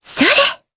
Yuria-Vox_Attack3_jp_b.wav